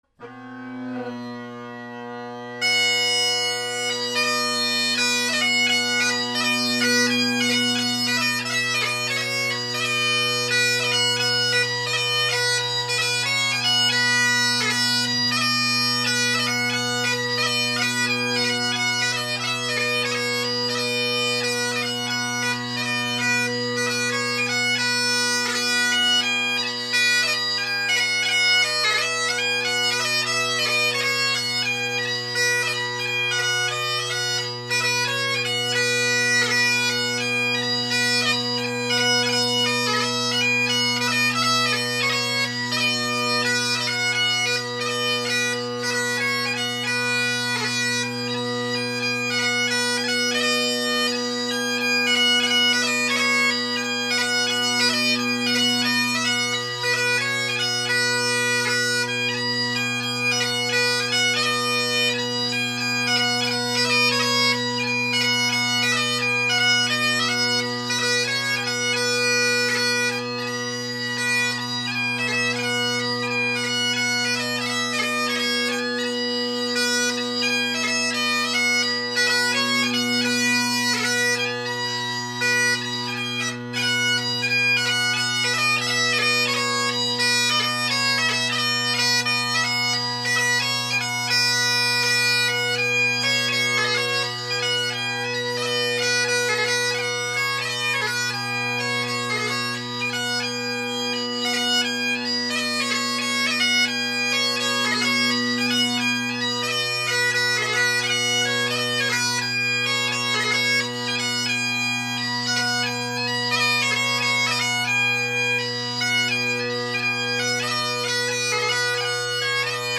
This chanter is louder than any other I’ve recorded, so pay attention to your speaker/headphone volume.
My Band’s 6/8 March Set – walking back and forth perpendicular to the mic.
The pipes played are my Colin Kyo bagpipe with Ezee tenors and short inverted Ezee bass.